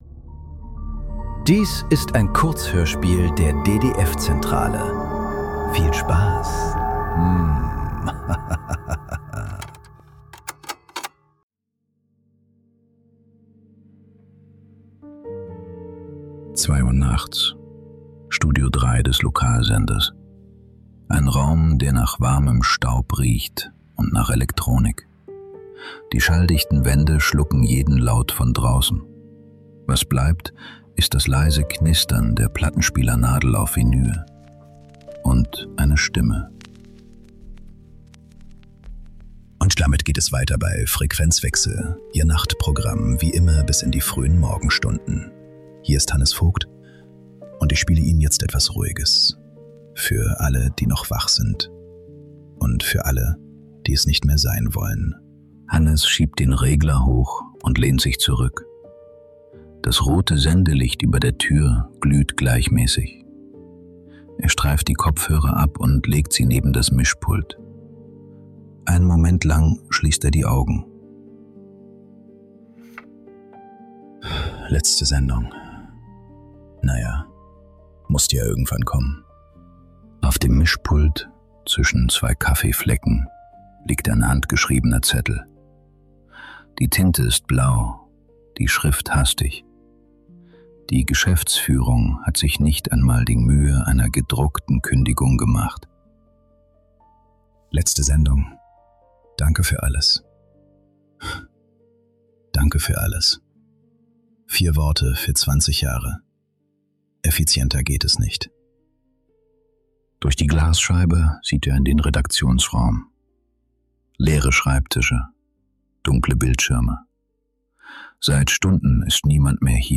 Frequenzwechsel ~ Nachklang. Kurzhörspiele.